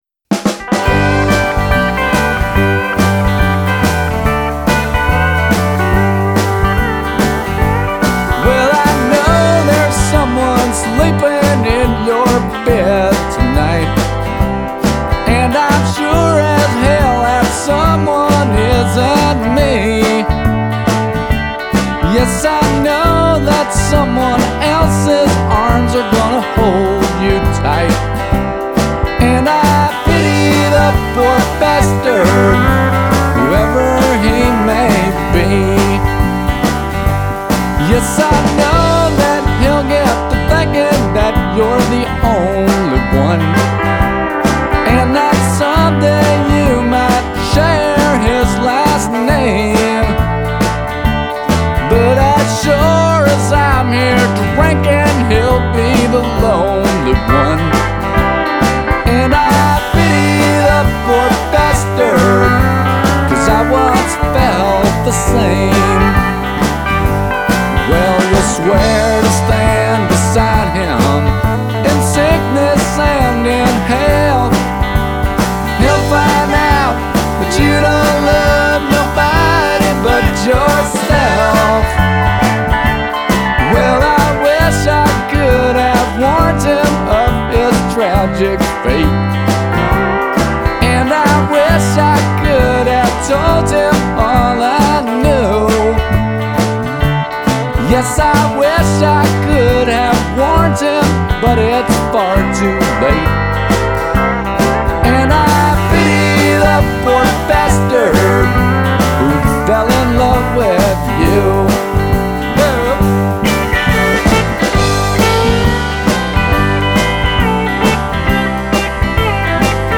alternative country band
Awesome, excellent sound.
vocals, drums
vocals, guitar